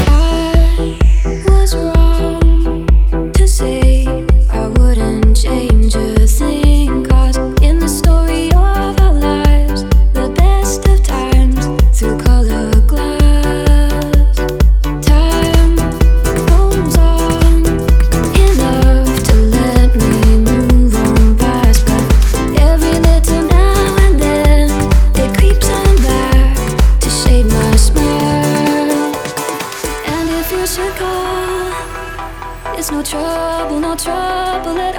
Жанр: Танцевальные / Электроника
Dance, Electronic